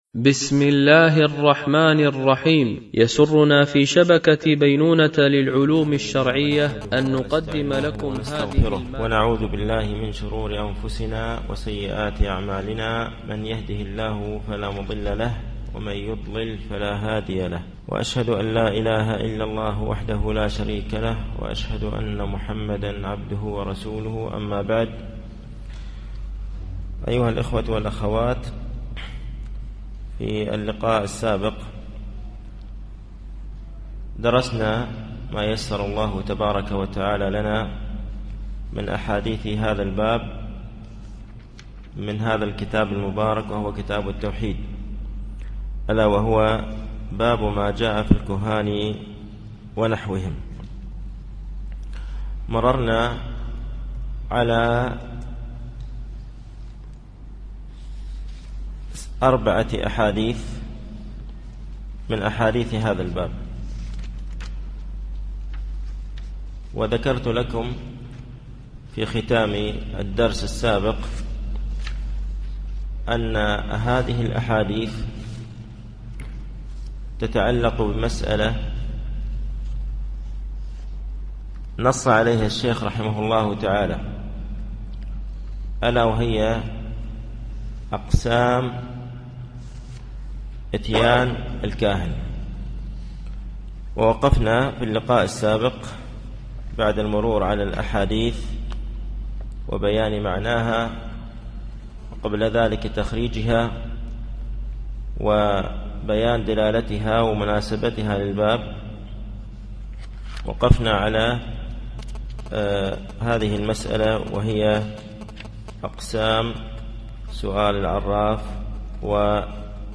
التعليق على القول المفيد على كتاب التوحيد ـ الدرس الخامس و الثمانون